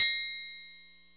SWTRNGLE.wav